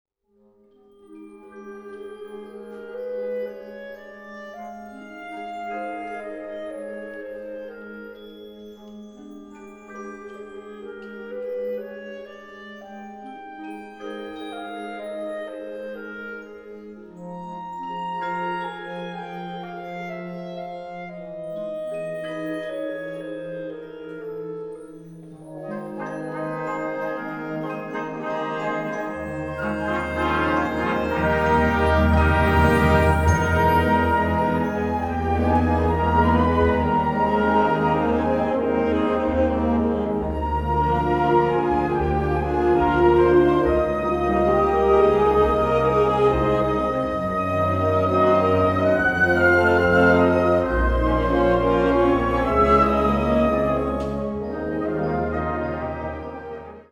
Besetzung Ha (Blasorchester)